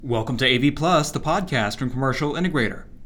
Below, you’ll find some audio samples recorded with three different microphones: the MXL Overstream bundle, a sub-$50 USB microphone made by a different manufacturer, and my iPhone 8’s voice recorder.
To keep these tests useful and consistent, I recorded them all in the same room and compressed the raw files into .MP3 format.
Here’s the sub-$50 USB mic:
The sub-$50 USB microphone sounded thin and brittle, and the audio using an iPhone wasn’t as rich or as detailed, either.